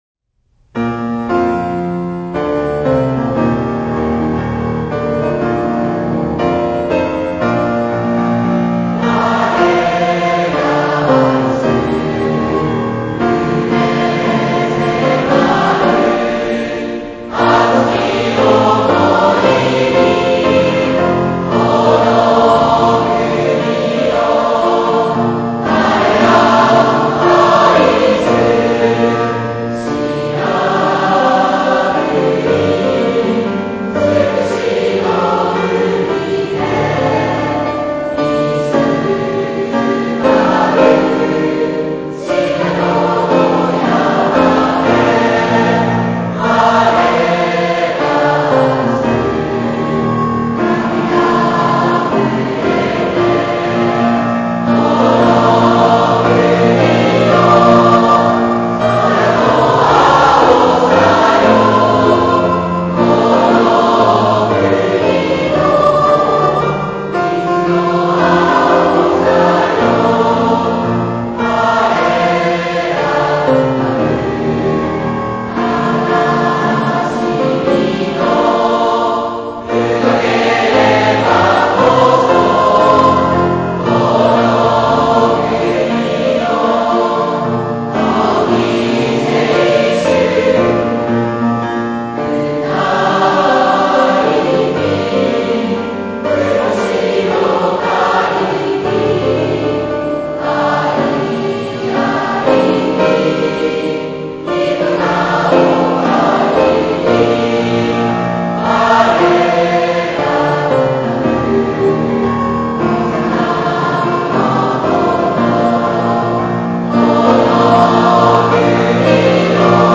（歌：平成16年度卒業生）